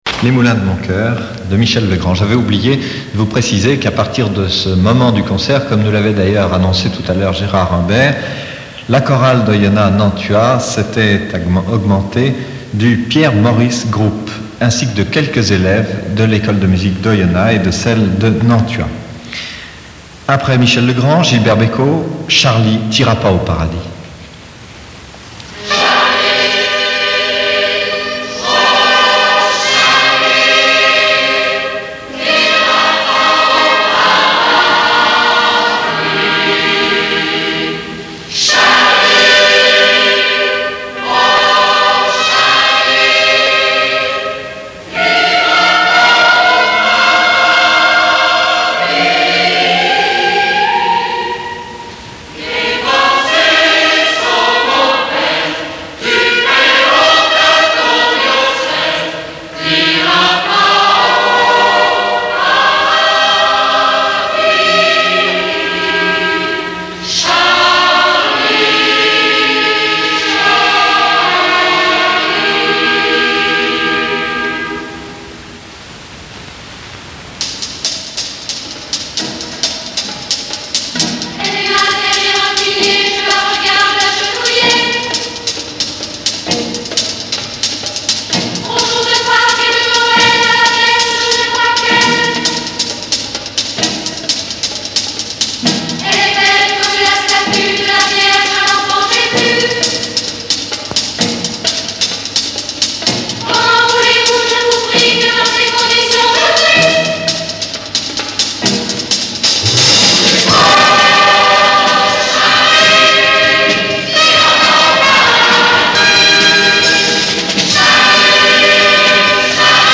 avec orchestre